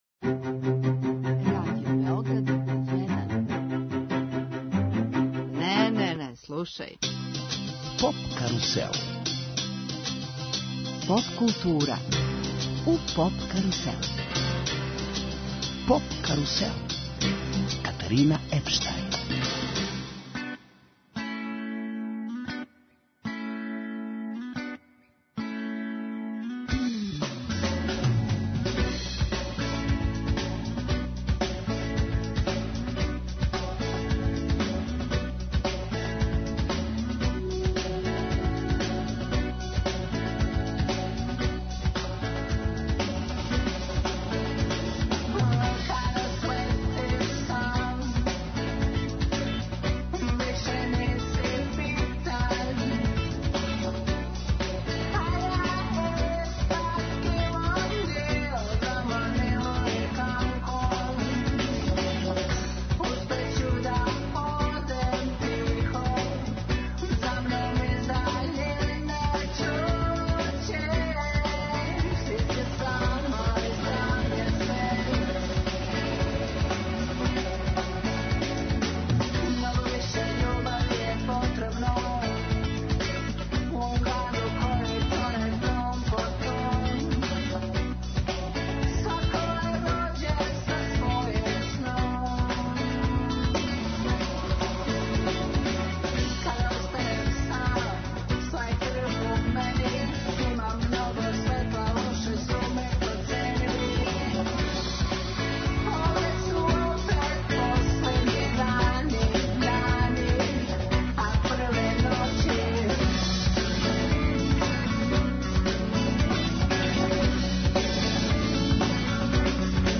Емисија из домена популарне културе.